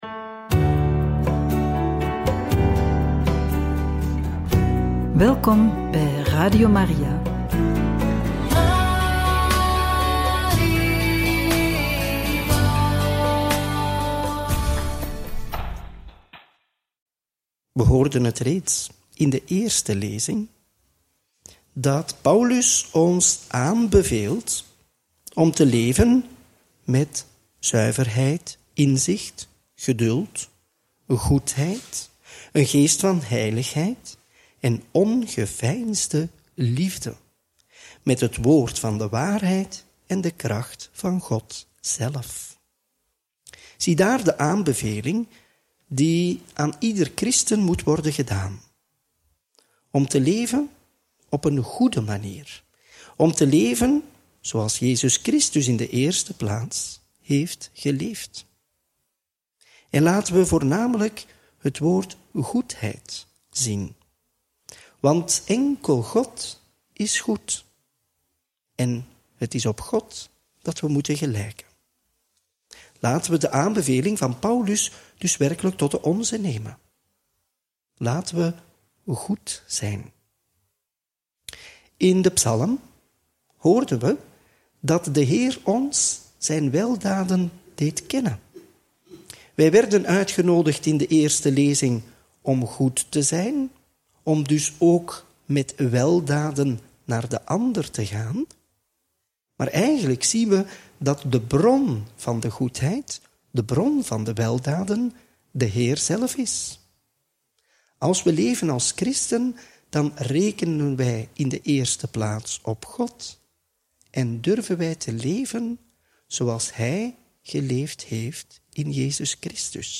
Homilie bij het Evangelie van maandag 16 juni 2025 – Mt 5, 38-42